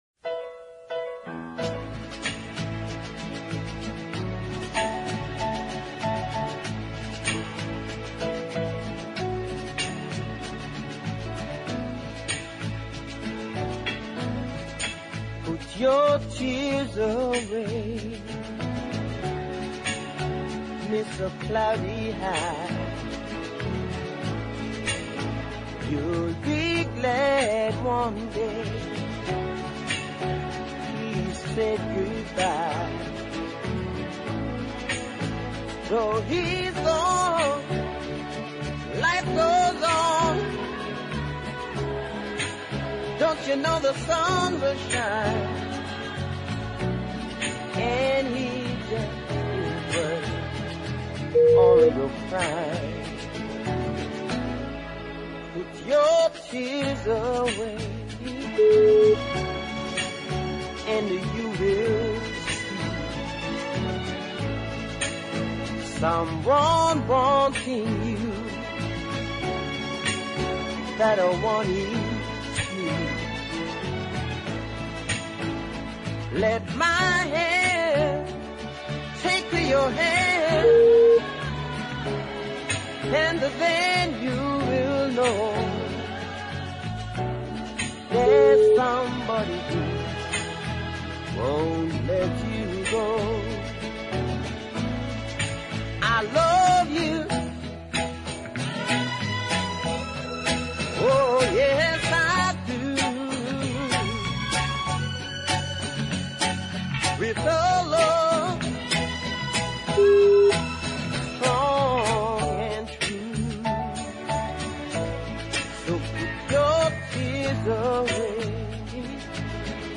sensational uptown ballad of great beauty